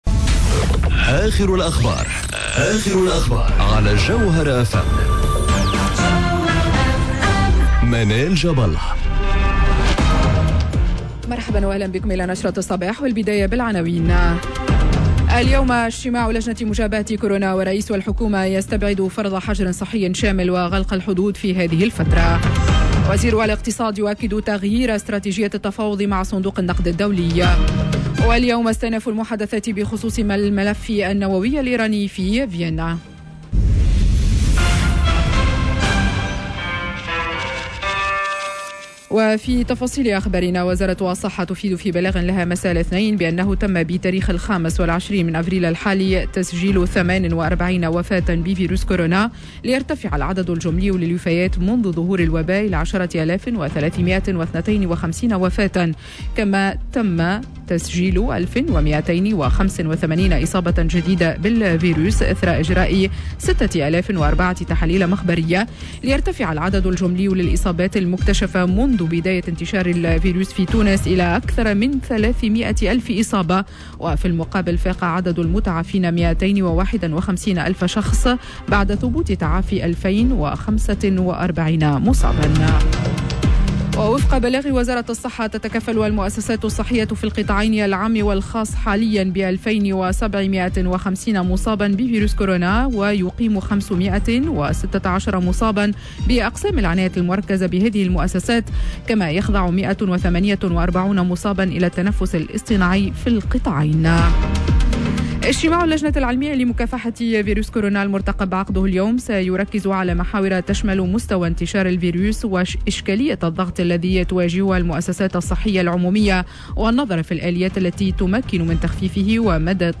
نشرة أخبار السابعة صباحا ليوم الثلاثاء 27 أفريل 2021